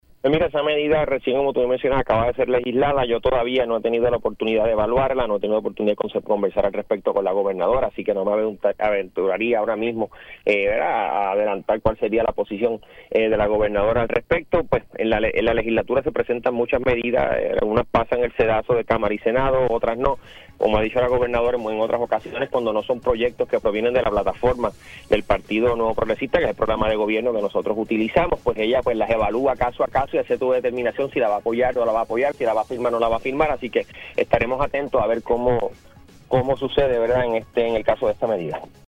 Por su parte, el secretario de Asuntos Públicos, Hiram Torres indicó en Pega’os en la Mañana que no está claro sobre la posición de la gobernadora Jenniffer González ante la medida.